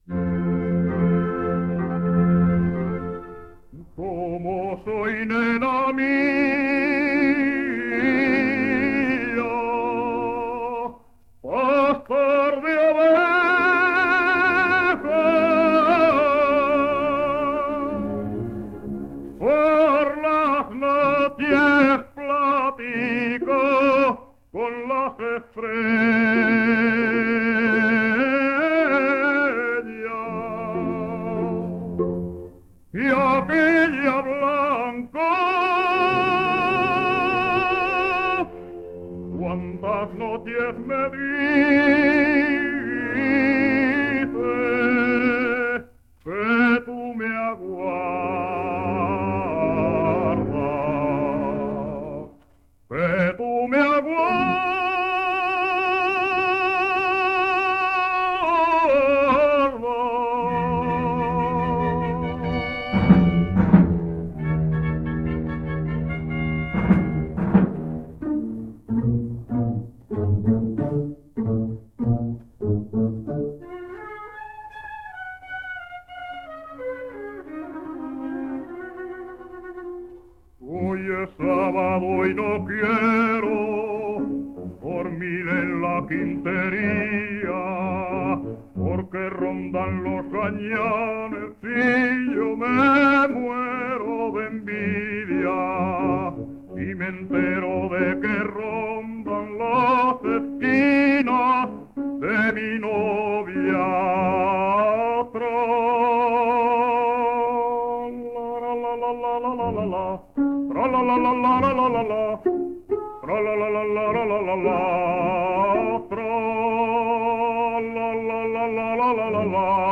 78 rpm